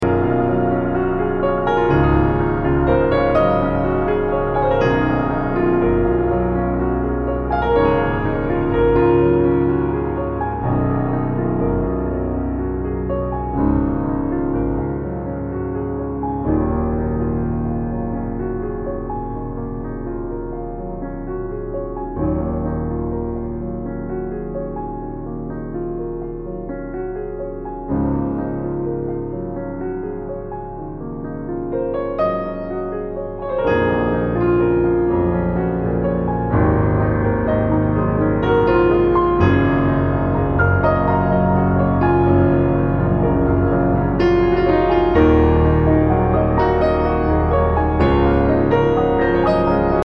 Post Classical >